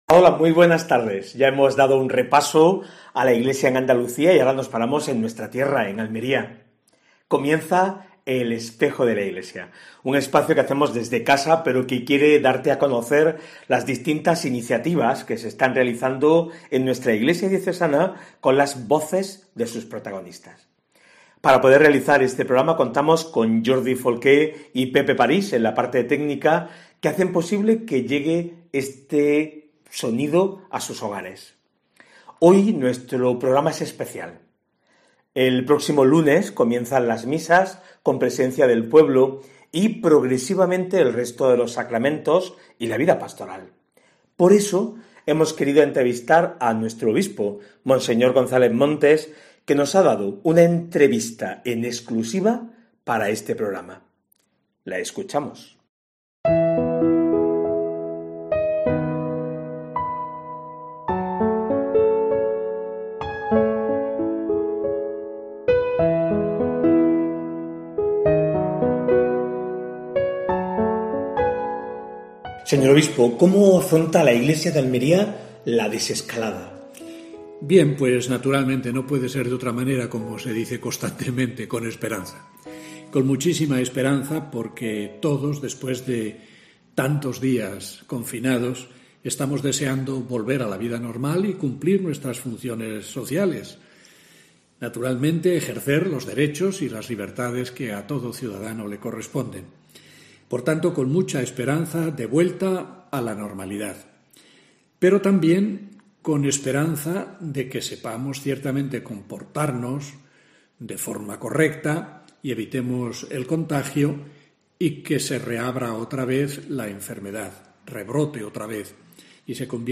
AUDIO: Actualidad en la Iglesia de Almería. Entrevista al Obispo de Almería (Monseñor Adolfo González Montes).